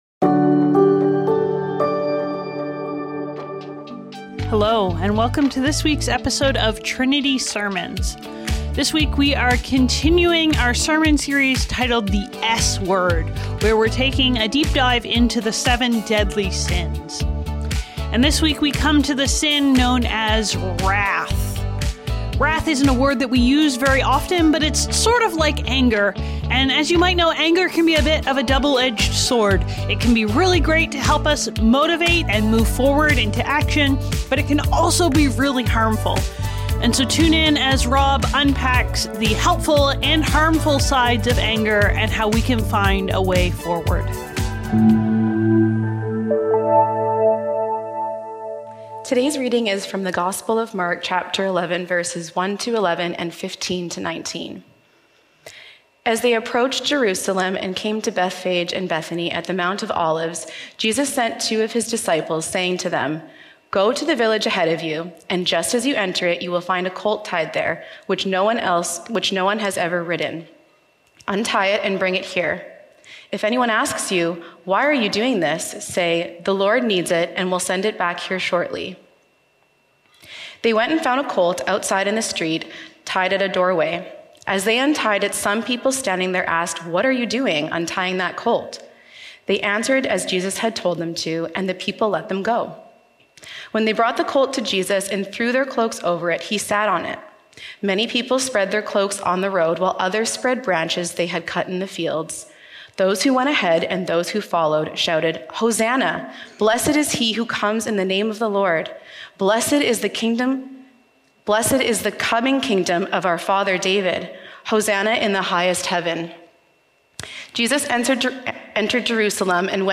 Trinity Streetsville - Wrestling with Wrath | The "S" Word | Trinity Sermons - Archive FM